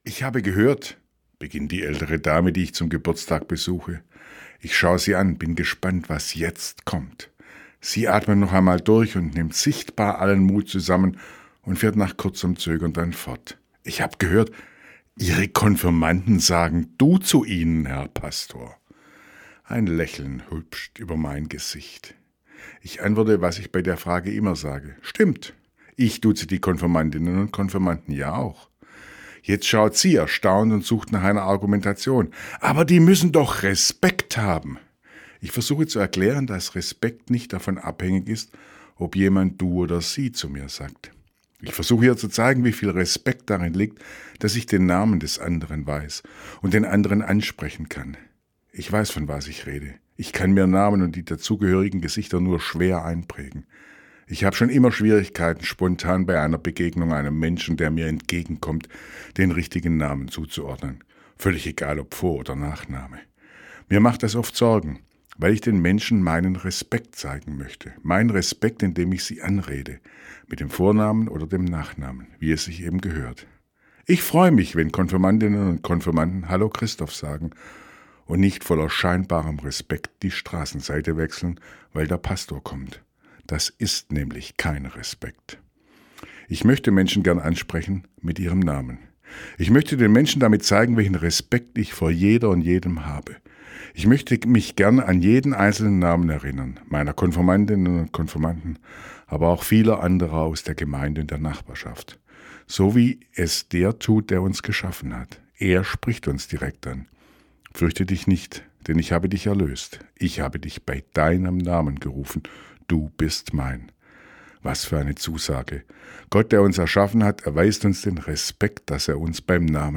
Radioandacht vom 9. Juli